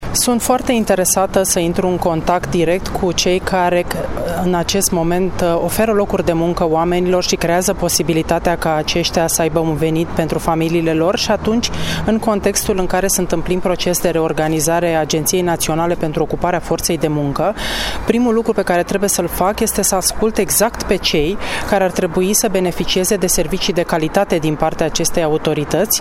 Preşedintele Consiliului Judeţean Braşov, Adrian Veştea, aminteşte că autorităţile locale braşovene se implică activ în pregătirea forţei de muncă: